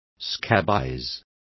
Also find out how sarnas is pronounced correctly.